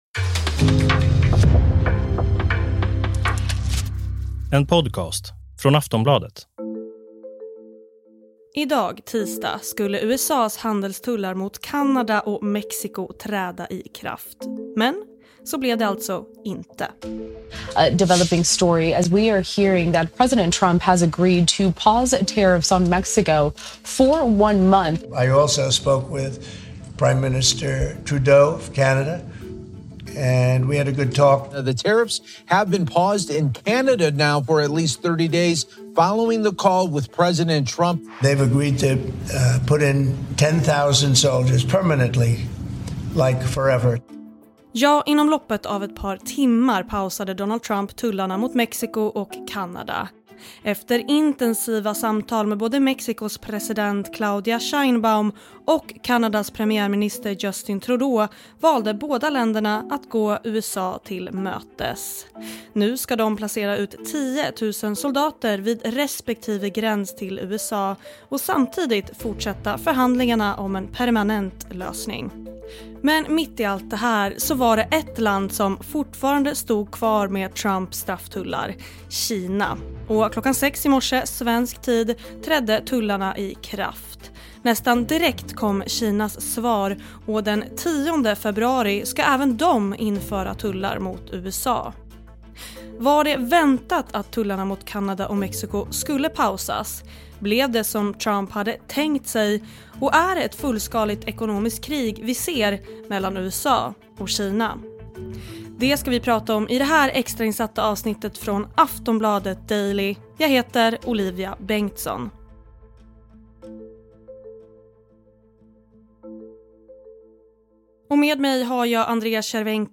Klipp i avsnittet: LiveNOW from Fox, City News, Nbc News.